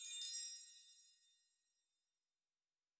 Magic Chimes 10.wav